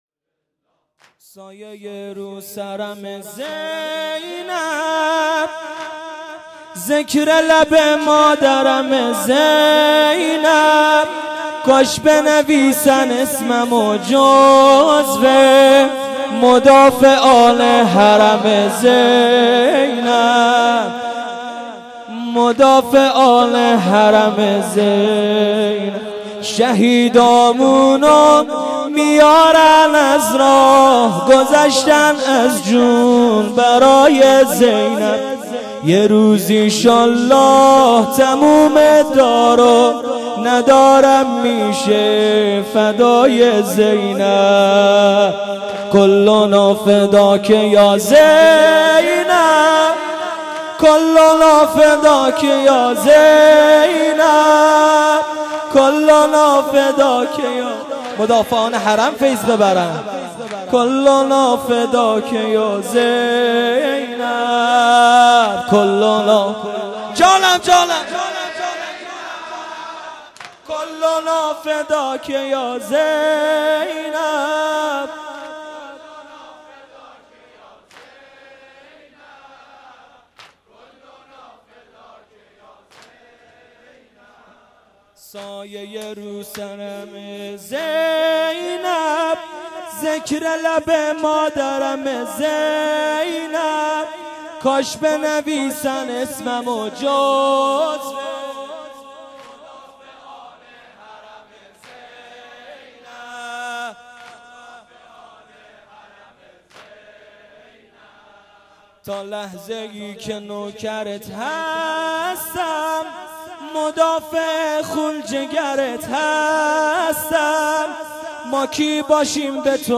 شب دوم محرم 1398